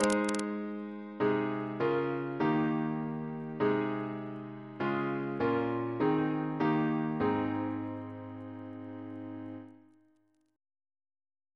Single chant in A♭ Composer: Samuel Arnold (1740-1802) Reference psalters: ACB: 257; H1940: 684; OCB: 43; PP/SNCB: 55; RSCM: 165